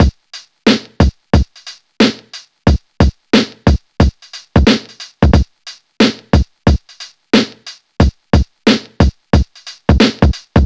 • 90 Bpm Drum Loop C# Key.wav
Free drum groove - kick tuned to the C# note. Loudest frequency: 928Hz
90-bpm-drum-loop-c-sharp-key-4xg.wav